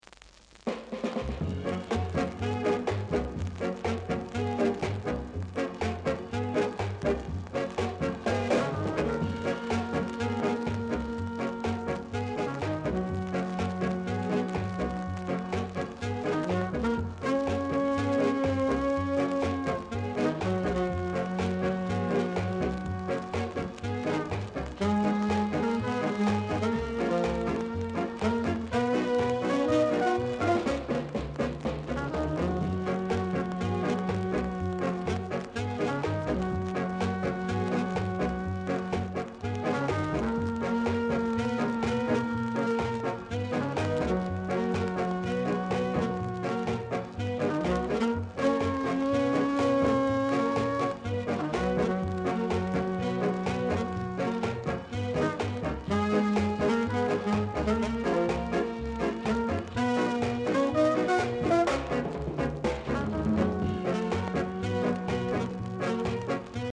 Inst
Hot ska vocal & nice inst!